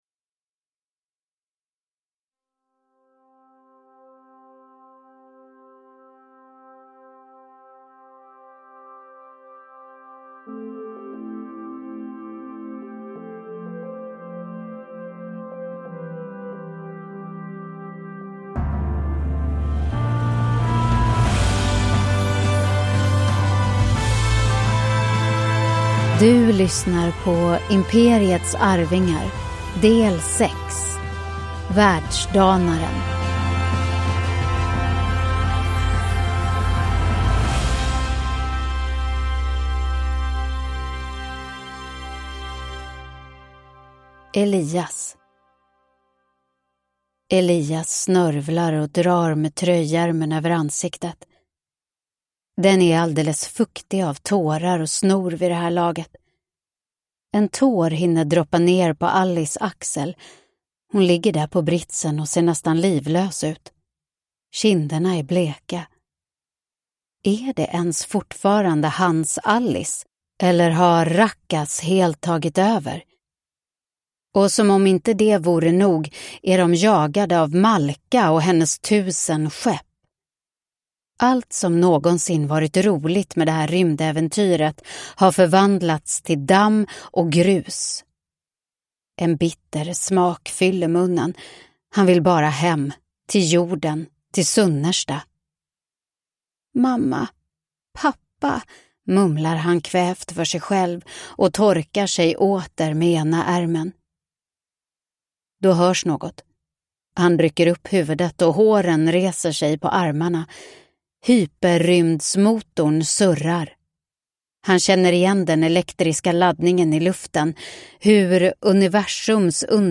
Världsdanaren – Ljudbok